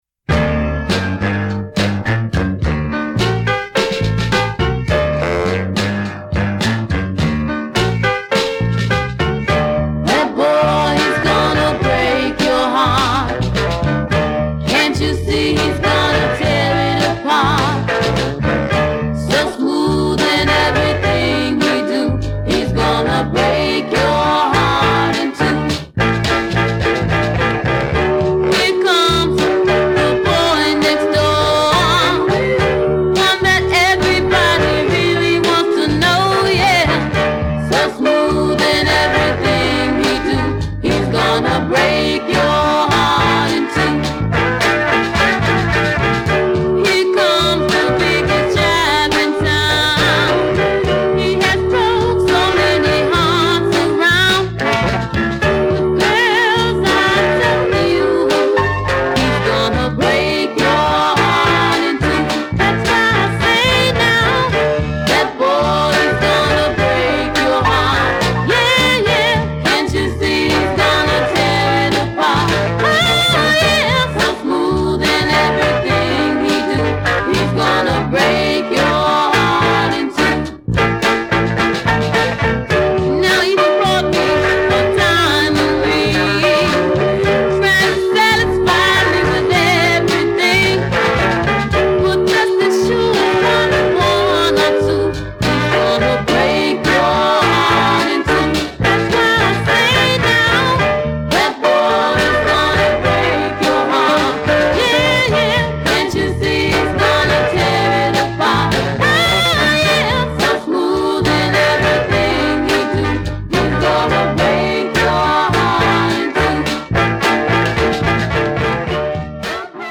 MASTER RECORDINGS - R&B / SOUL